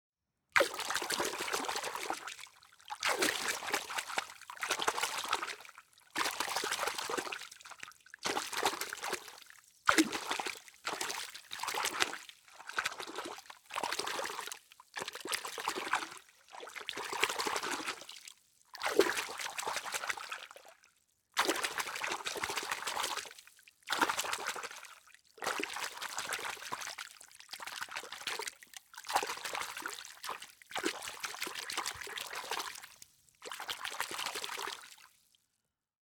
Звук плеска рыбы в ведре